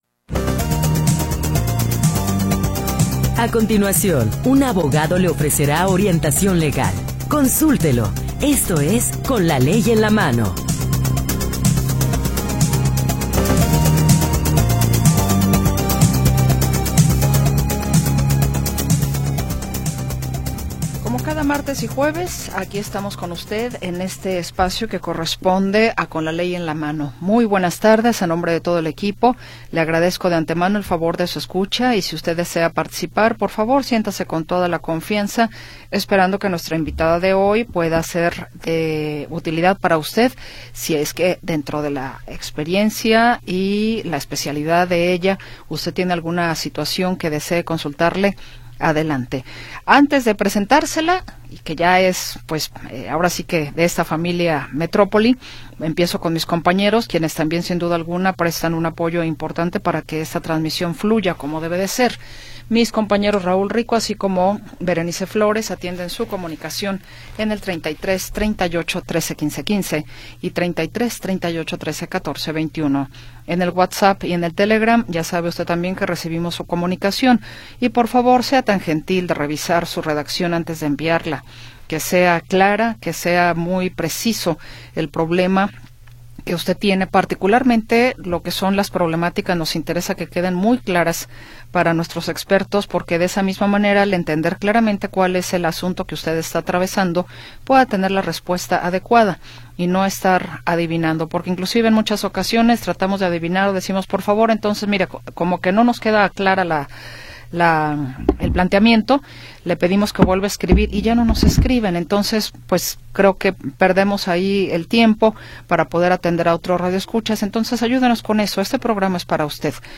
1 Metrópoli al Día 2da Hora - 17 de Diciembre de 2024 47:46 Play Pause 1h ago 47:46 Play Pause Lejátszás később Lejátszás később Listák Tetszik Kedvelt 47:46 La historia de las últimas horas y la información del momento. Análisis, comentarios y entrevistas